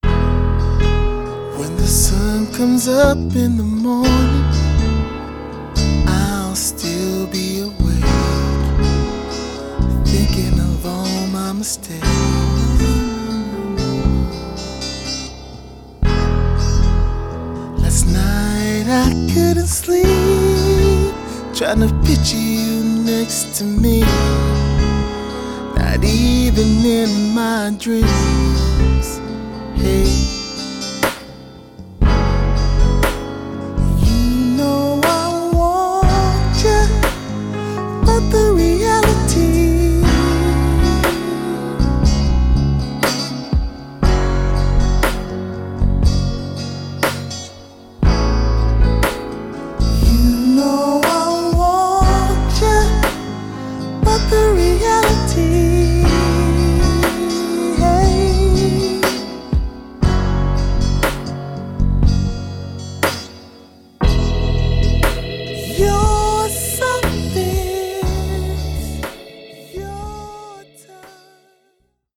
Mastering Samples